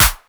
snr_34.wav